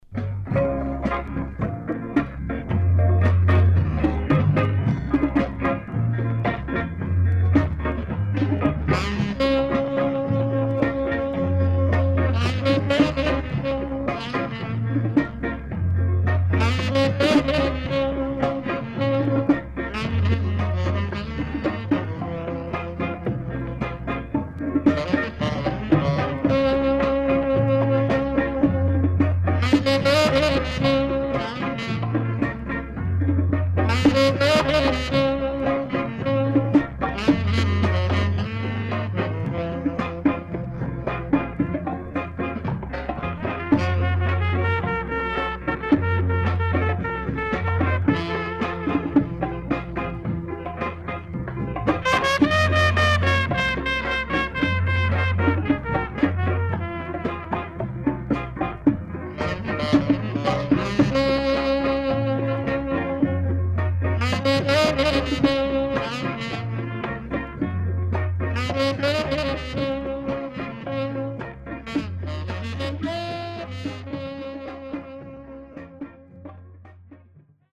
Nice Ska Inst & Carib Mood Inst